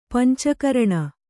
♪ panca karaṇa